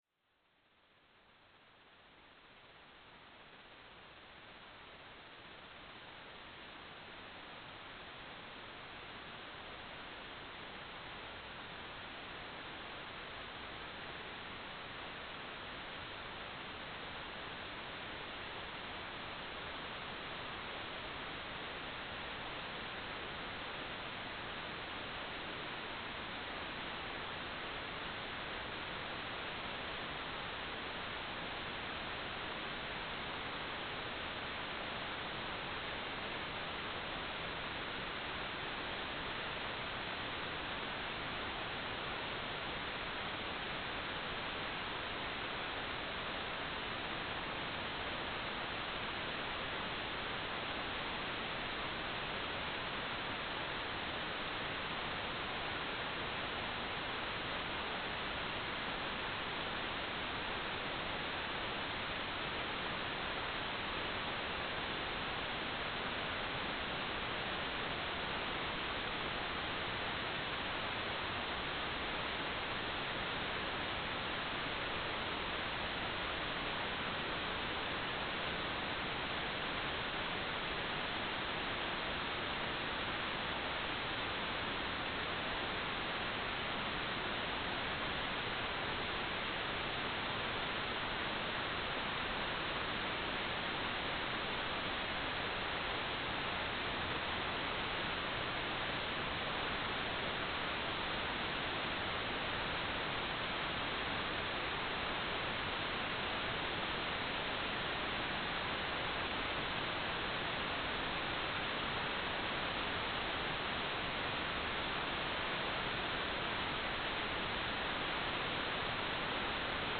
"transmitter_description": "CW",
"transmitter_mode": "CW",